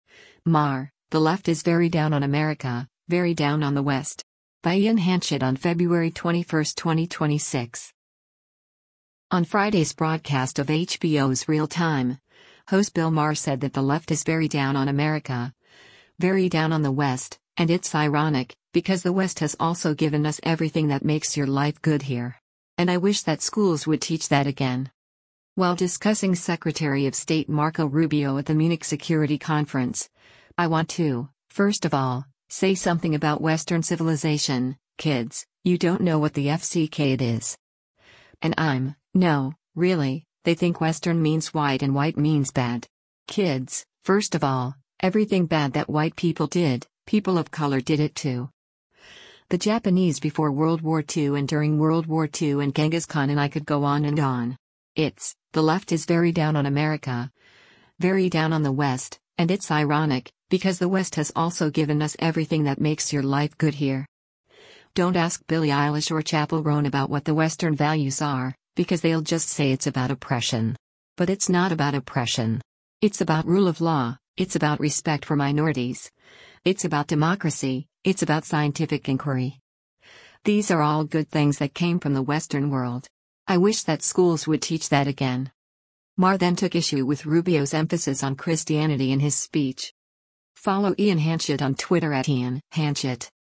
On Friday’s broadcast of HBO’s “Real Time,” host Bill Maher said that “the left is very down on America, very down on the West, and it’s ironic, because the West has also given us everything that makes your life good here.”